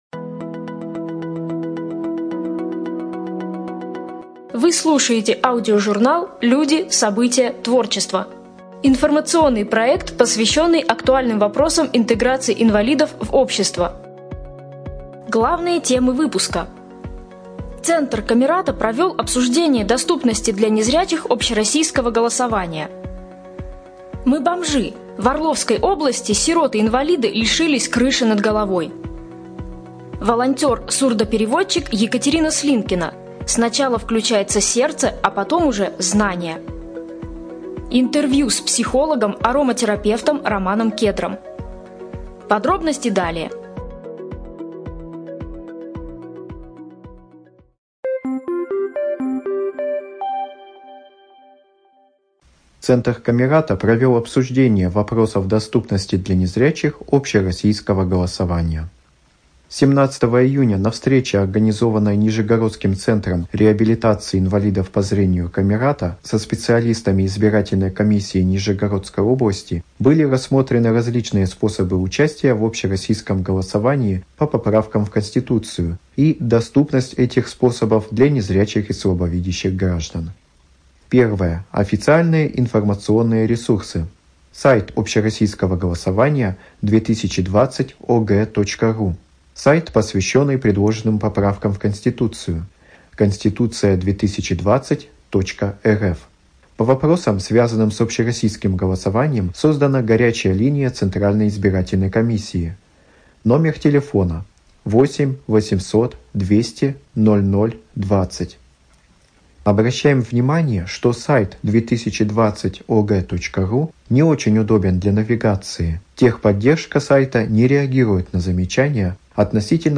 ЖанрРеабилитация, Публицистика